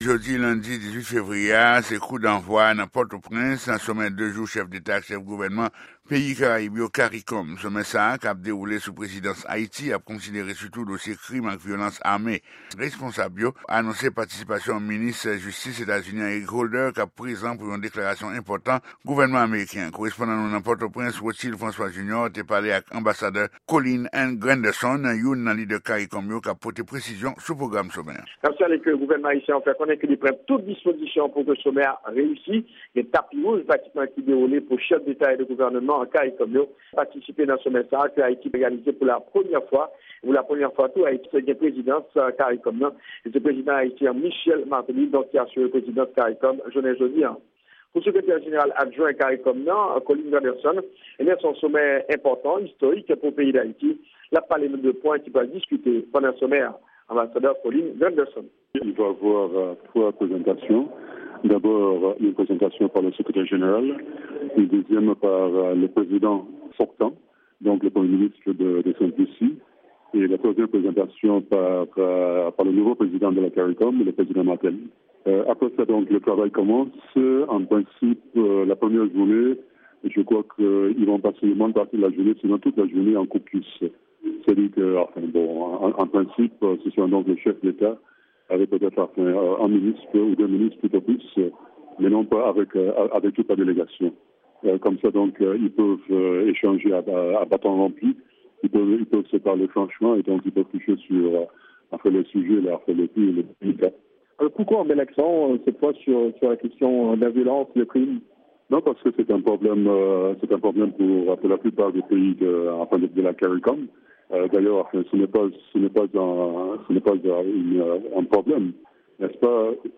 Repòtaj Sèvis Kreyòl Lavwadlamerik la